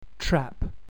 British English
American English
TRAP